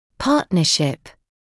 [‘pɑːtnəʃɪp][‘паːтнэшип]сотрудничество; партнерство